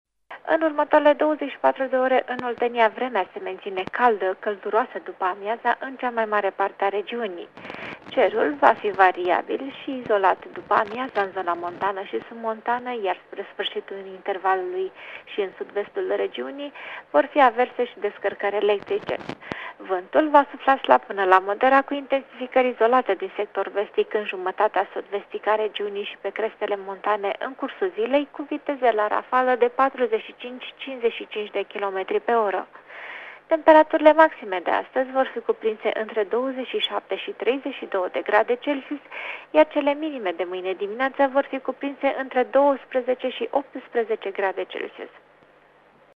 Prognoza meteo 16 iulie (audio)